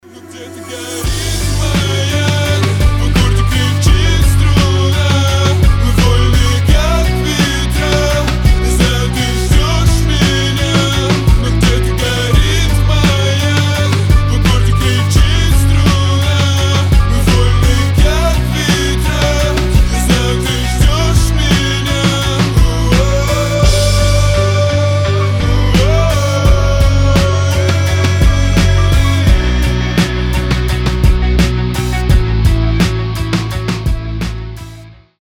• Качество: 320, Stereo
громкие
сильные